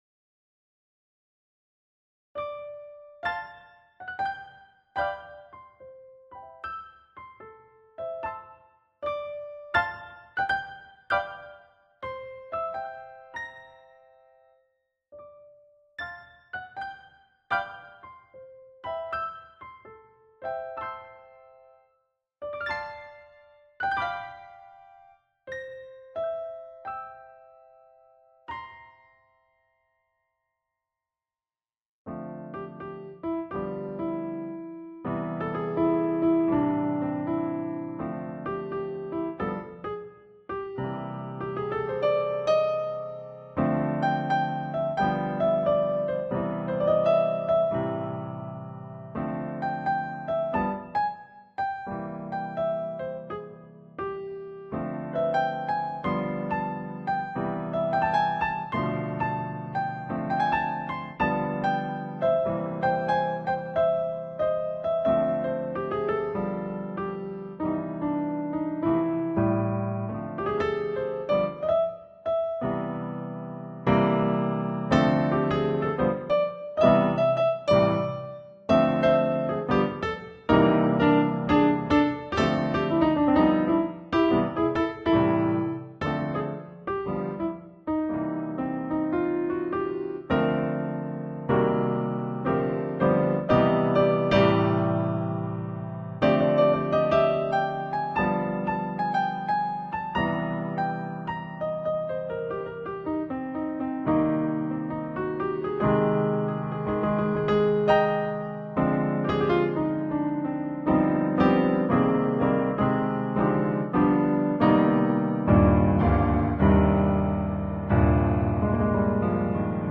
-Wat ik hier speelde is een improvisatie, daarom weet ik niet of ik het ooit een tweede keer precies zo kan herhalen.- Eerst wilde ik dit liedje alleen maar positief maken want meestal wordt een "ideale wereld" gezien en beschouwd als iets positiefs.
Dus, koos ik er voor om het leven van een mens te beschrijven met muziek, want wat is nou idealer dan leven? In sommige stukken is het fraai en de andere wat intenser, maar Yang kan er niet zijn zonder Yin du